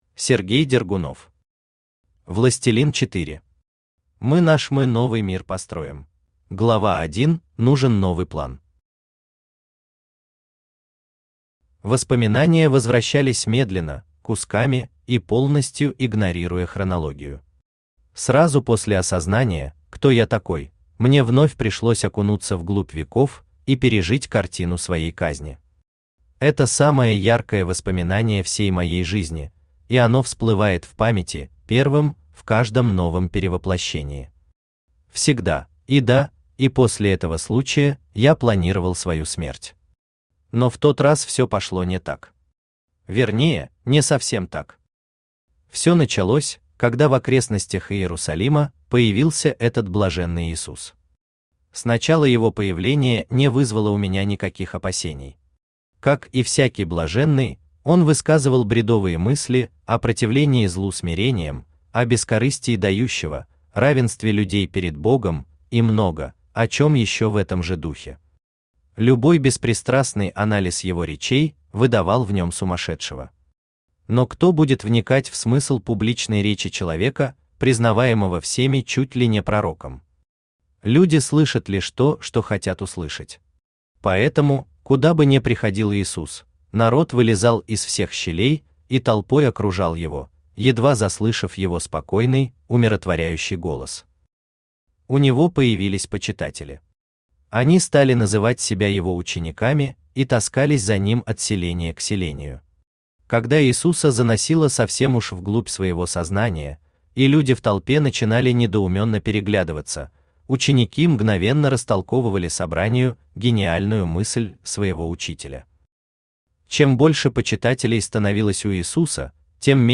Аудиокнига Властелин 4. Мы наш мы новый мир построим | Библиотека аудиокниг
Aудиокнига Властелин 4. Мы наш мы новый мир построим Автор Сергей Николаевич Дергунов Читает аудиокнигу Авточтец ЛитРес.